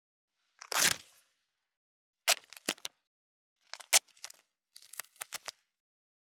165.ガムテープ【無料効果音】